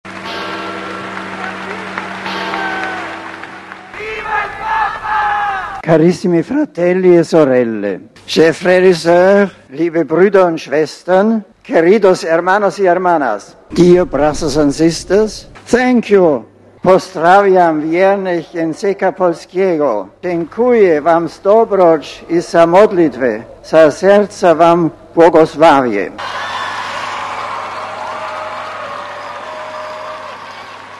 Papież Benedykt XVI pozdrowił po polsku Polaków, uczestniczących w audiencji generalnej w Watykanie.
Znajdujące się początkowo w tekście pozdrowienia słowo "pielgrzymów" papież zastąpił łatwiejszym do wymówienia słowem "wiernych".